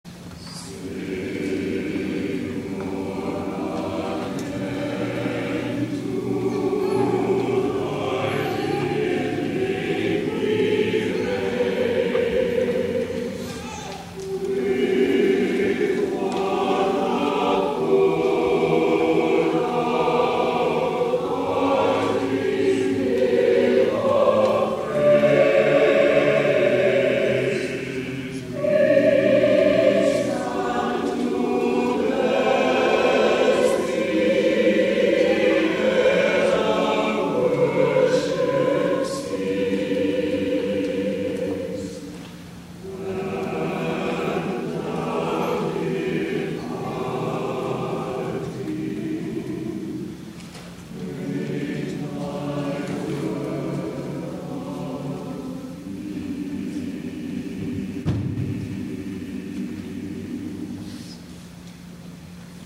*THE CHORAL RESPONSE